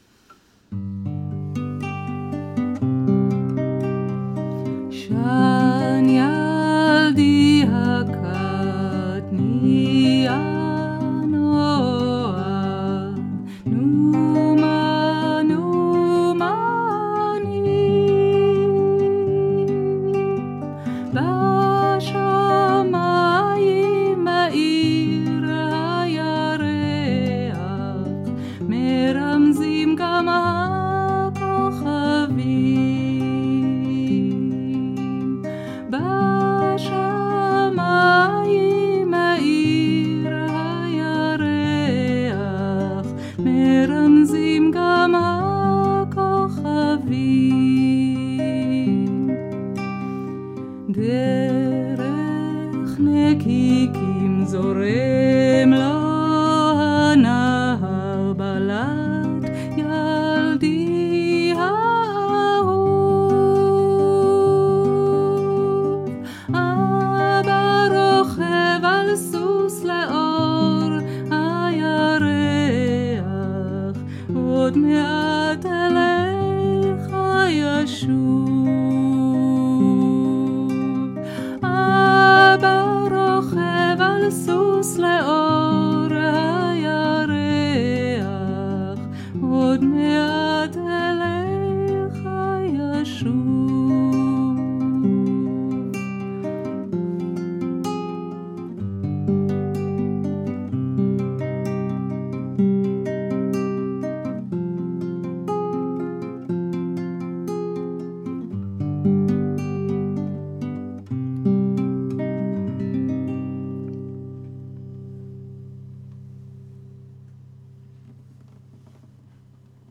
שיר ערש "שן ילדי הקט".